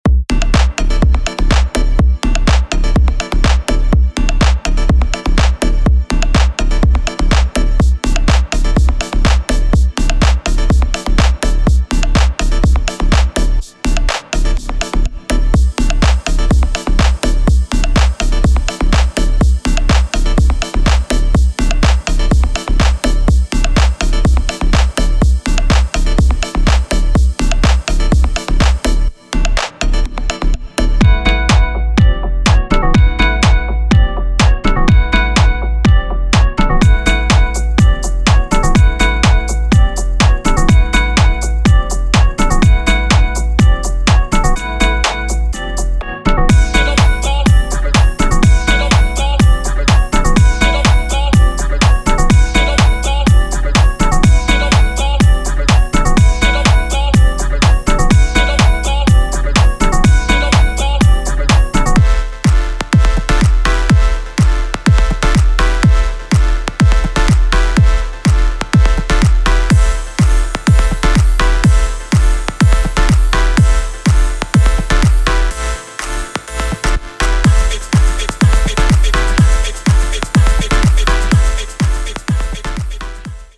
这是一幢深沉，旋律的房子，正向着前瞻性的制作人倾诉，发出清晰，纯净的声音。